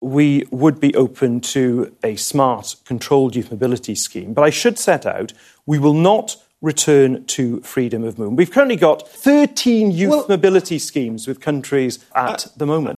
The UK’s Minister for EU Relations, Nick Thomas-Symonds says it’s too early to confirm anything…………..